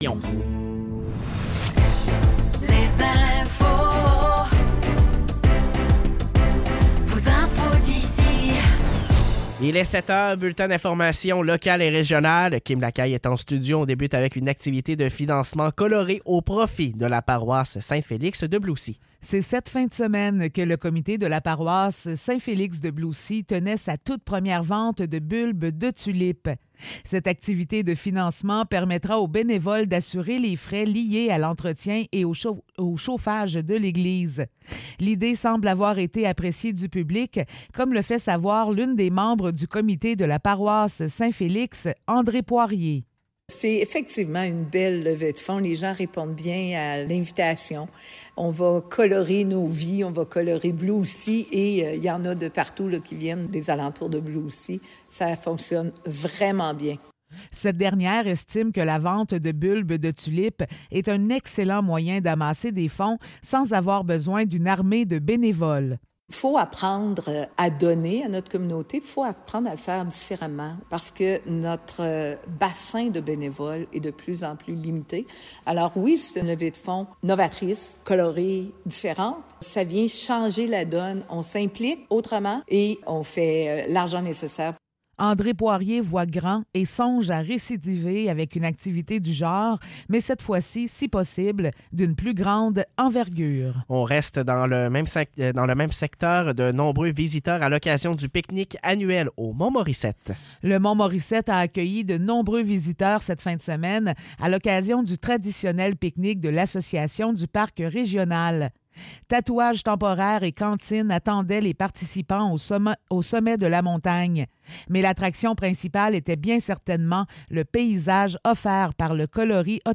Nouvelles locales - 7 octobre 2024 - 7 h